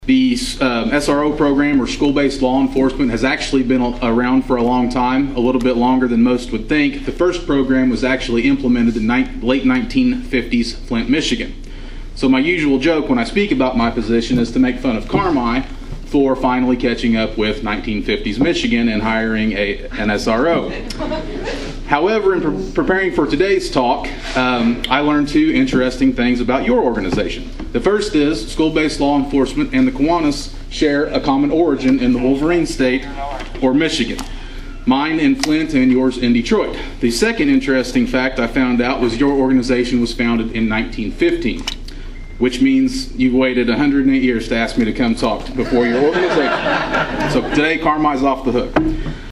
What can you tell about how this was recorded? He spoke Thursday at the weekly Carmi Kiwanis Club meeting.